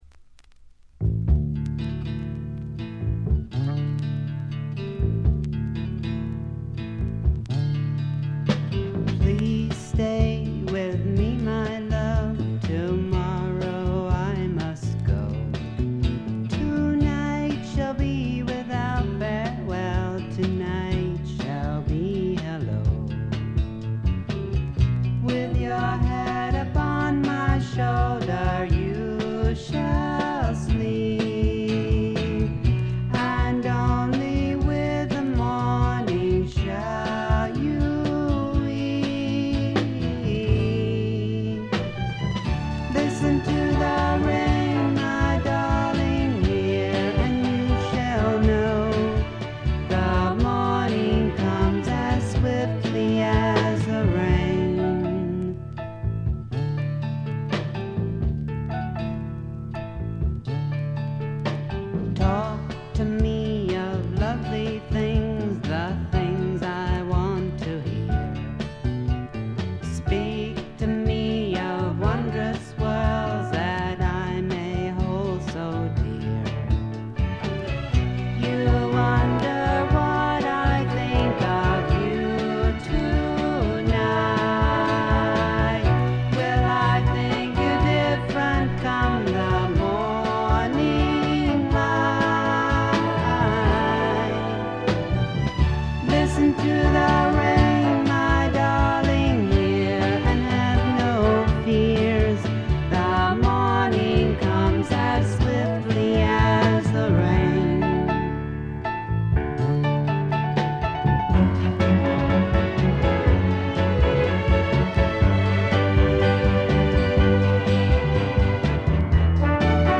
軽微なバックグラウンドノイズ、チリプチ程度。
ソフト・ロック、ソフト・サイケ、ドリーミ・サイケといったあたりの言わずと知れた名盤です。
試聴曲は現品からの取り込み音源です。